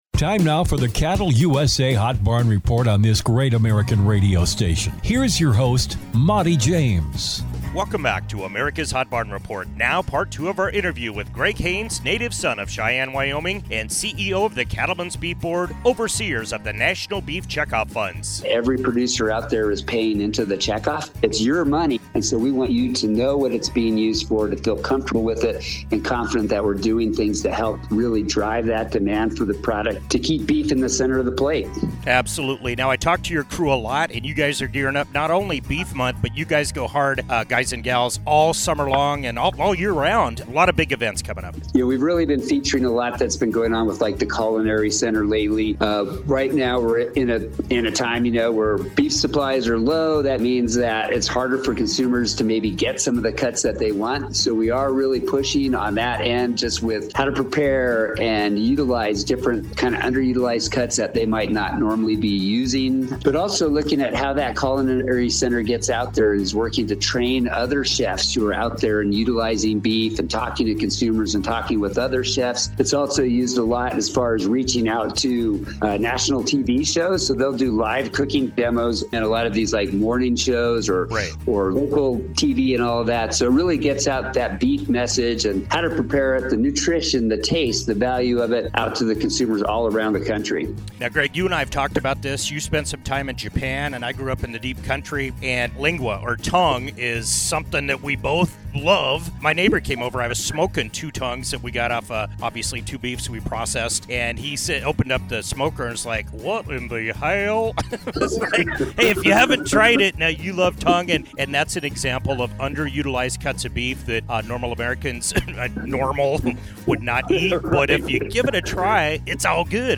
HOT BARN REPORT: Special Interview